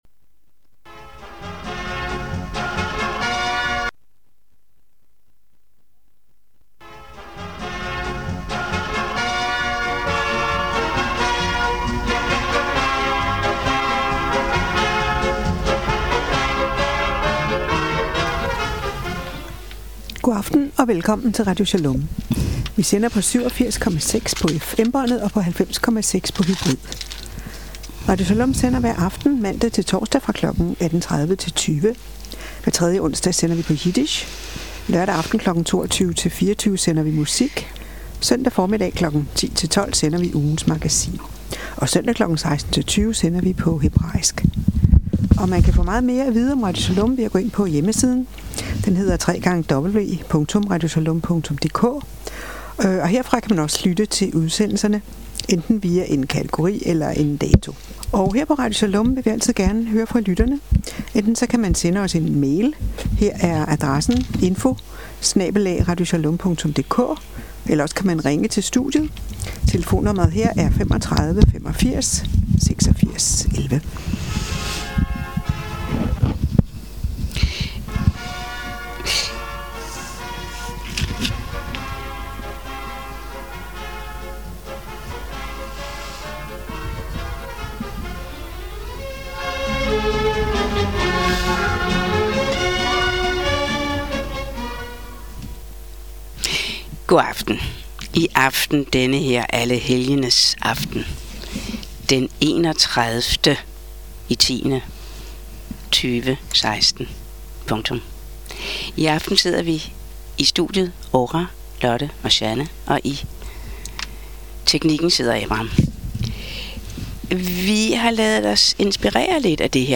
Beskrivelse:Diskussion over uhygge i jødedomen